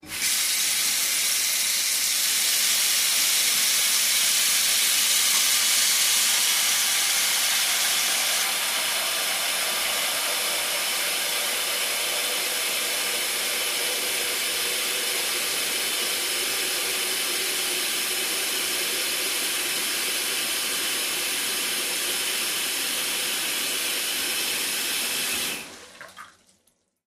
fo_sink_rundrainclos_02_hpx
Bathroom sink water runs with drain open and closed.